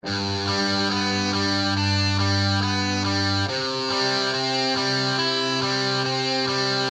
电动采指法
描述：这是一些用我的扭曲器进行的指弹。
标签： 120 bpm Rock Loops Guitar Electric Loops 1.15 MB wav Key : Unknown
声道立体声